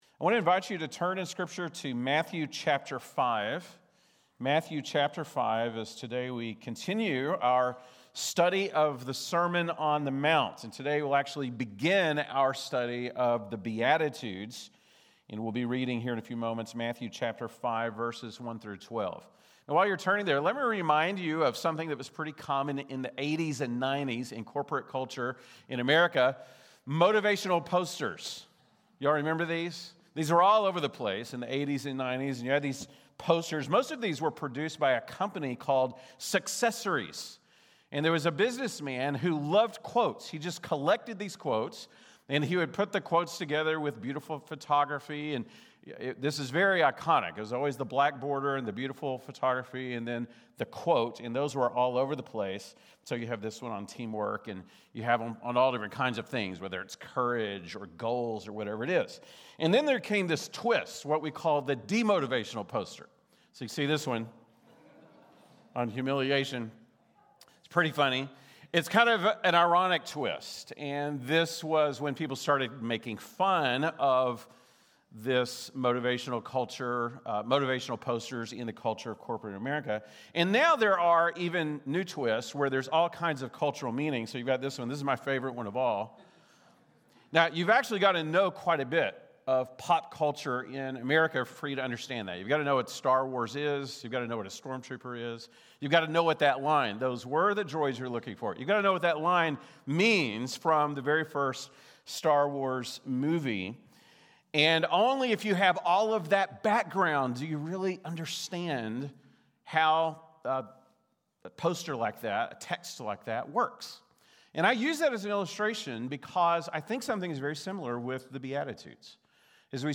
October 19, 2025 (Sunday Morning)